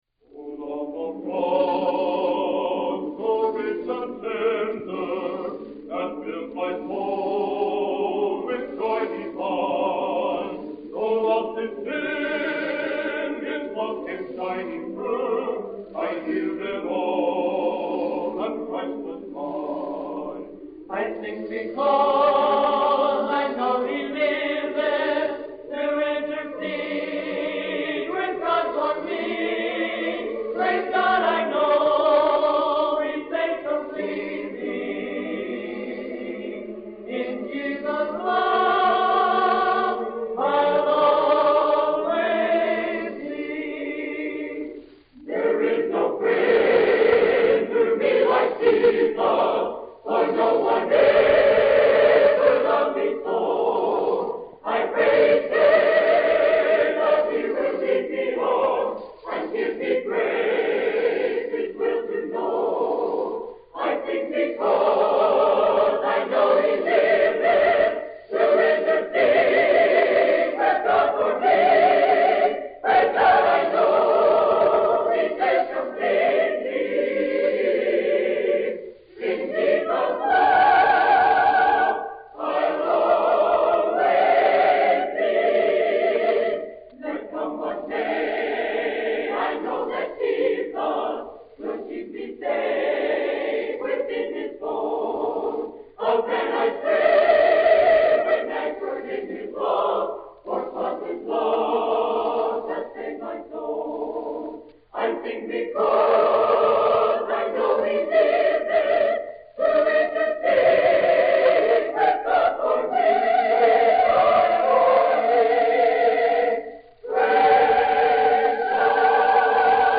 This is a direct-to-disc recording of the Bethany Nazarene College A Cappella Choir Annual Tour from 1962-1963 year.